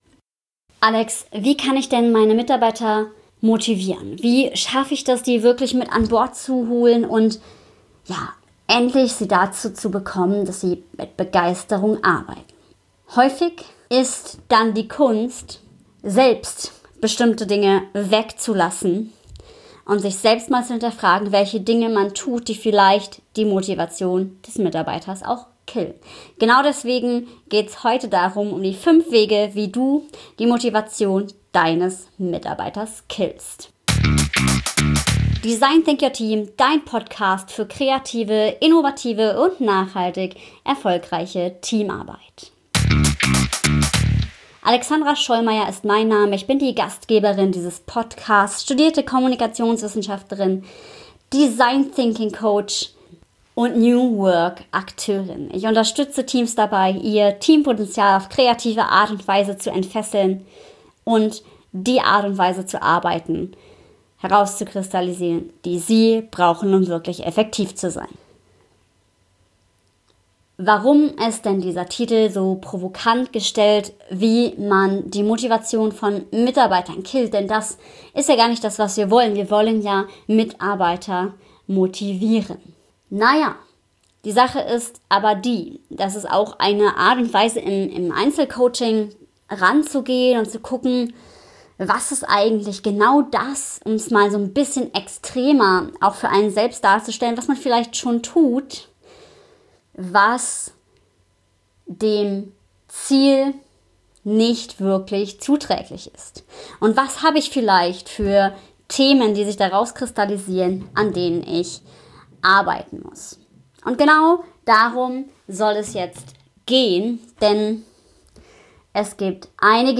Teil 2: Passion at Work: Wie Leidenschaft dein Team zum Erfolg führt - Interview